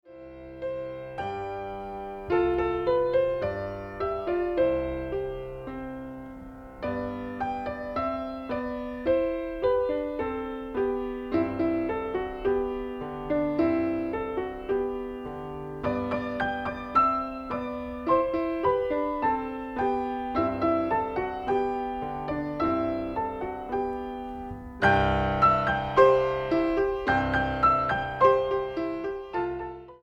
Piano/Organ Ensembles Piano Duets
Piano Duet